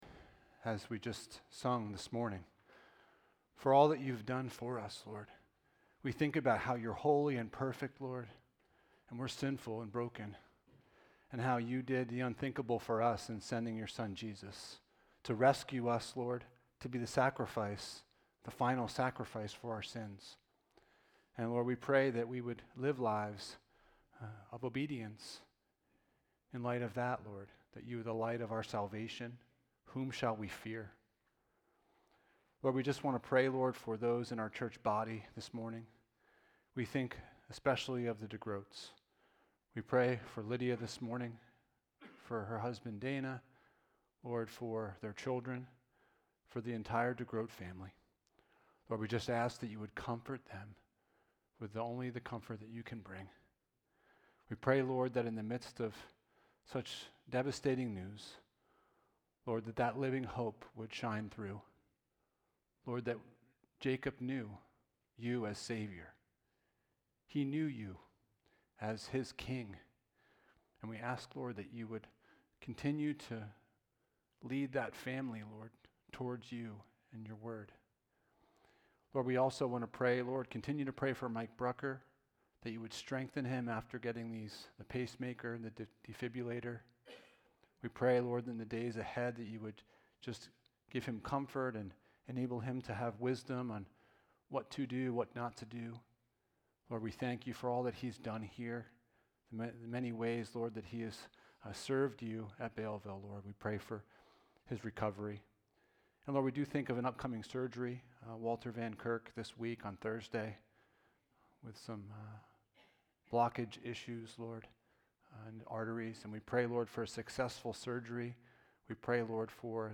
Habakkuk 3:16-19 Service Type: Sunday Morning « Do I Have a Deeply Rooted Faith?